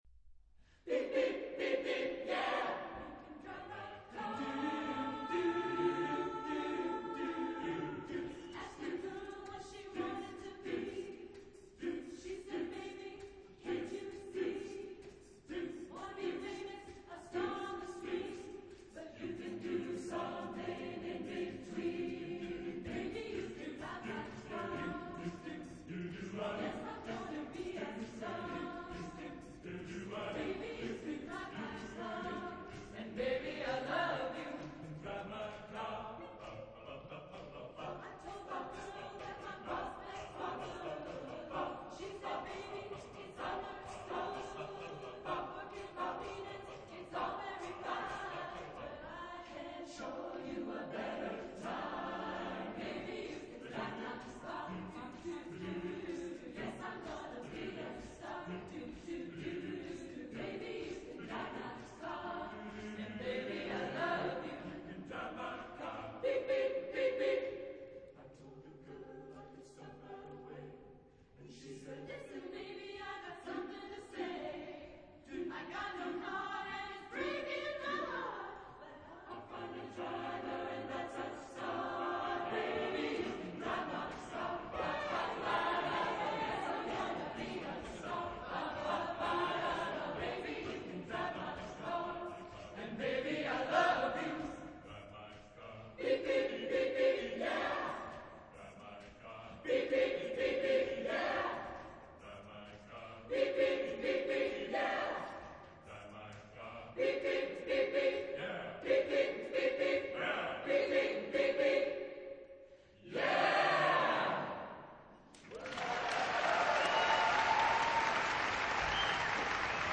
Genre-Style-Form: Popsong ; Vocal jazz ; Secular
Mood of the piece: light
Type of Choir:  (5 mixed voices )
Tonality: D major